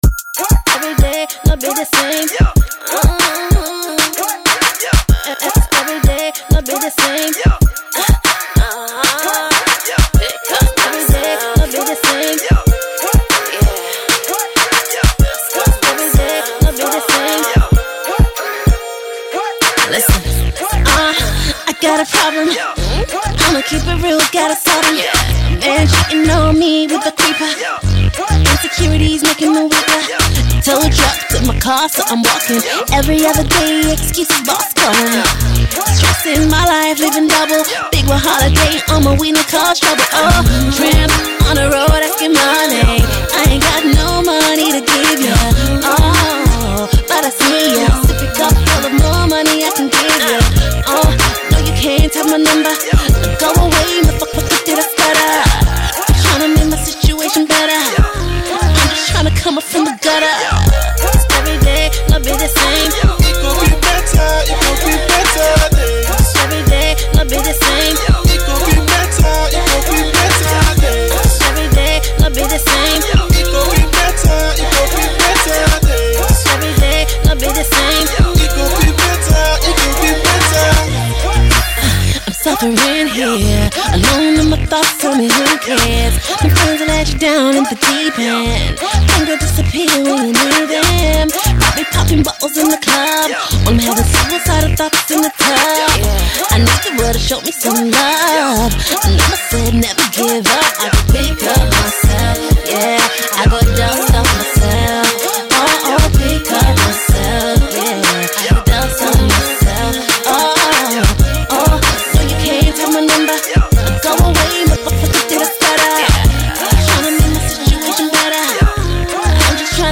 UK singer